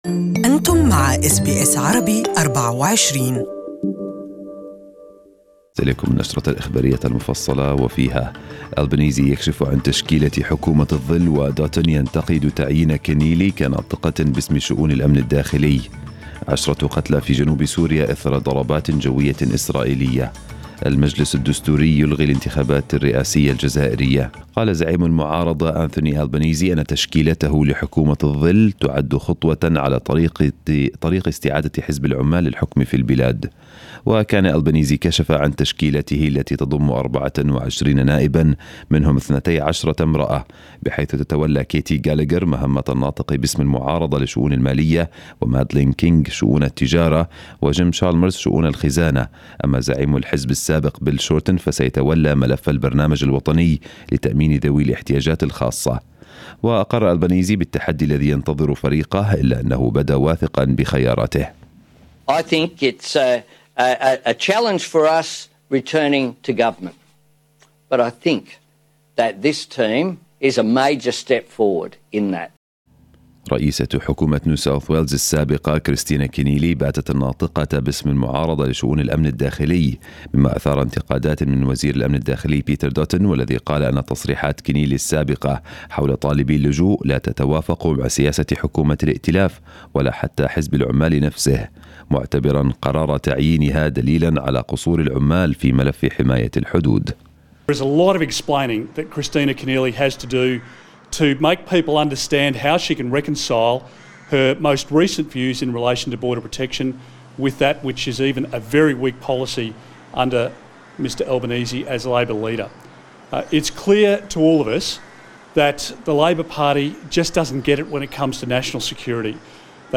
Morning News bulletin in Arabic.